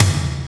Index of /90_sSampleCDs/Roland L-CD701/TOM_Rolls & FX/TOM_Tomish FX
TOM FAT T05R.wav